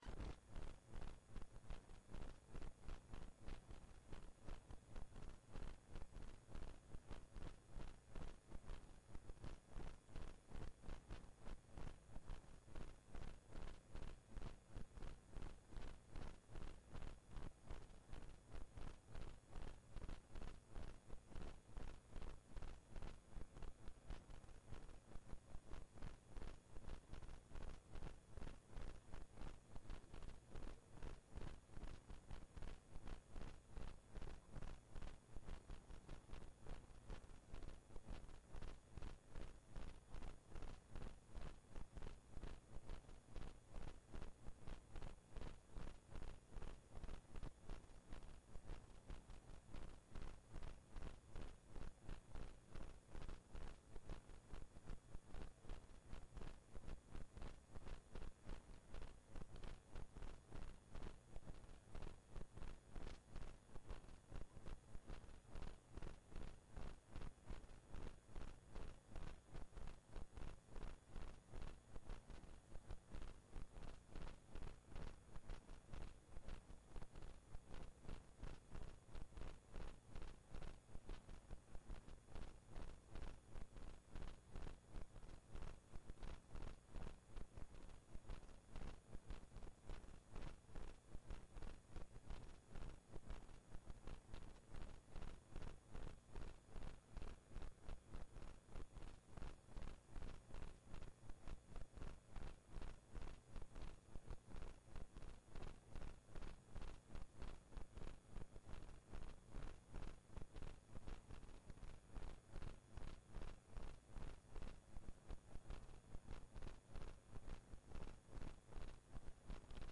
Kjemiforelesning 2